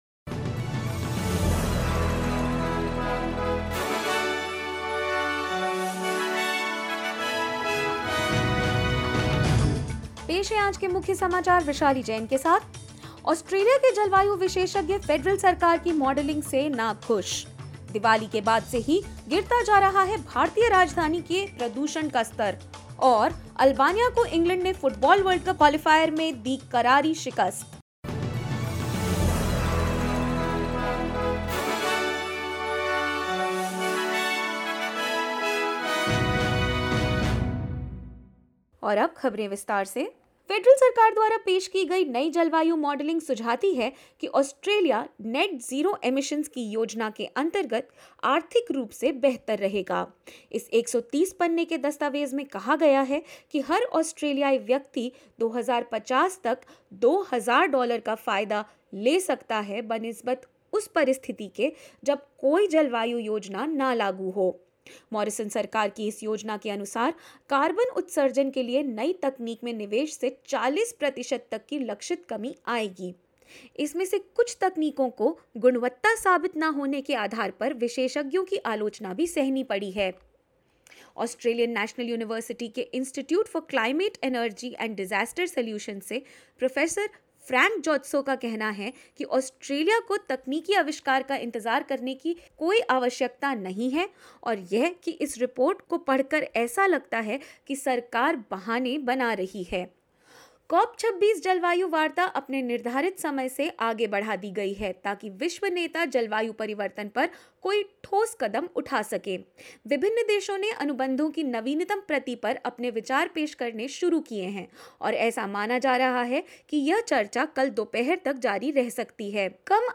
In this latest SBS Hindi news bulletin of Australia and India: Federal government's plans for emission reduction are receiving criticism from Australian climate experts; COP26 talks extended as leaders try to reach an agreement and more.
hindi_news_1311.mp3